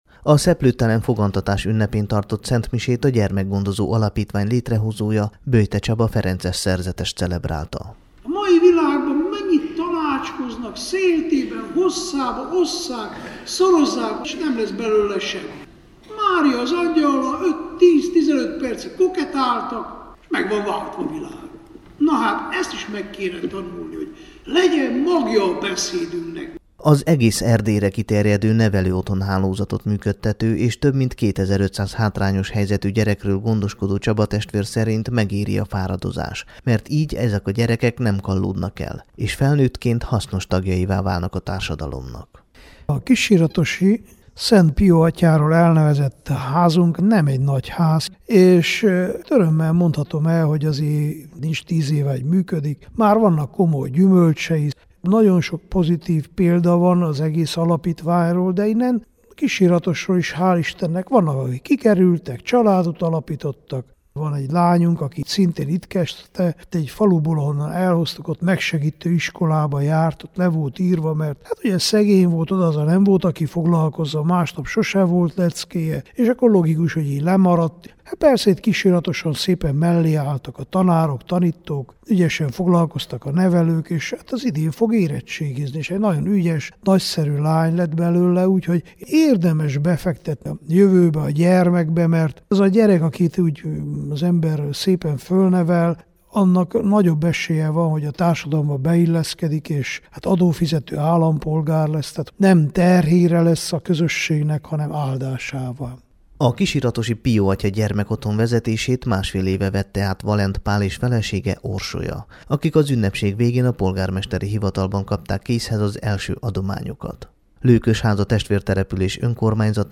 A helyi római katolikus templomban tartott ünnepségen fellépett a kisiratosi citerazenekar és vegyes kórus, valamint az iskola énekkara.
jotekonysagi_musor_a_kisiratosi_gyermekotthon_javara.mp3